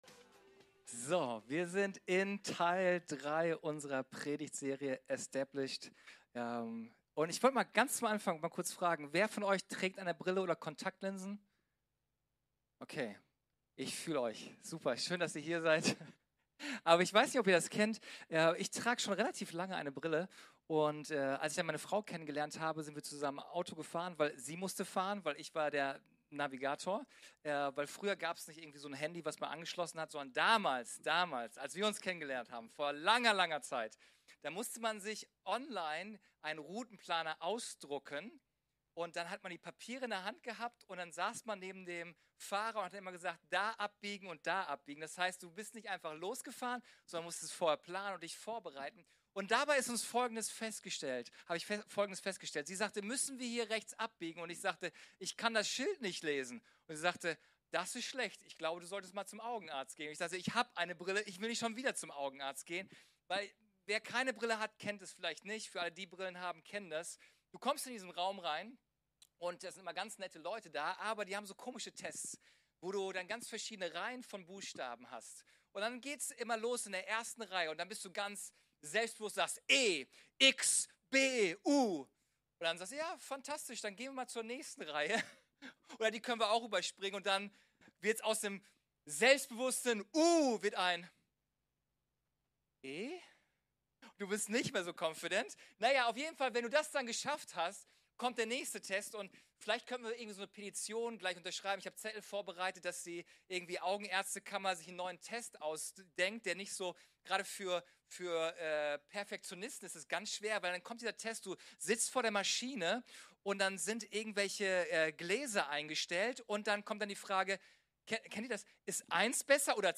Unsere Predigt vom 17.09.23 Predigtserie: Estabished Folge direkt herunterladen